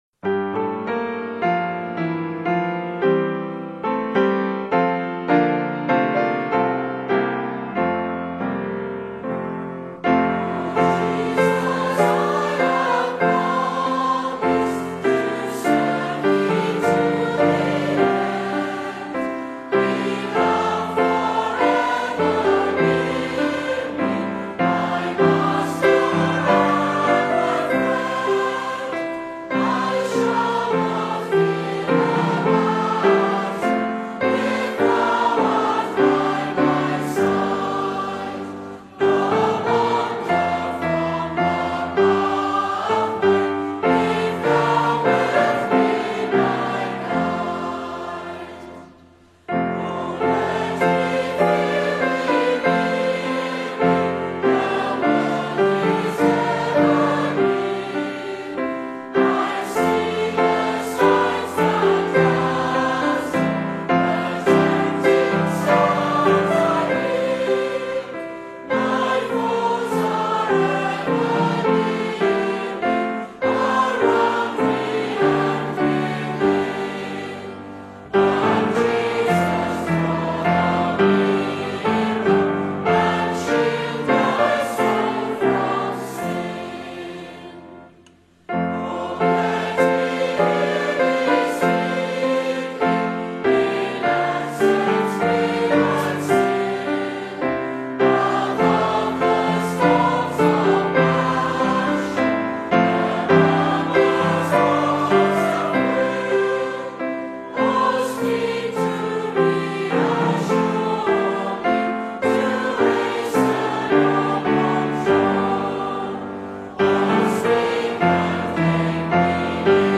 Top Christian Hymns